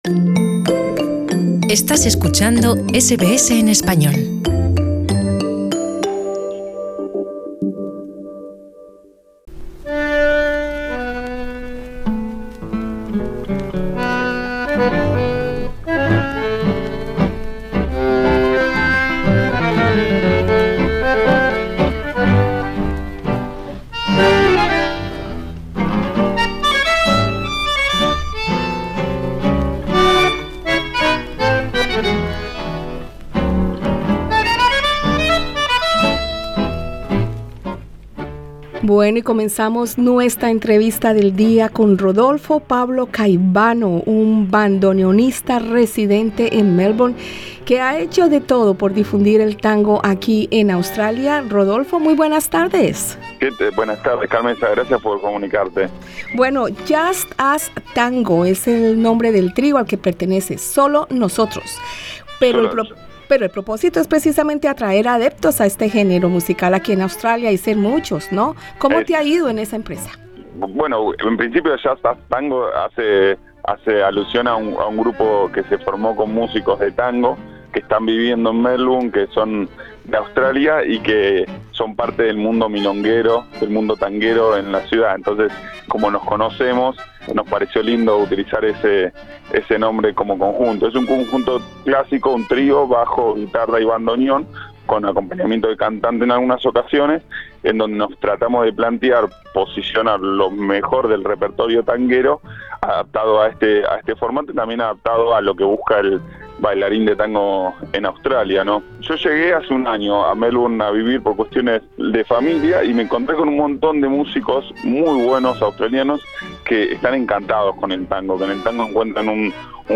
Escucha arriba en nuestro podcast la entrevista.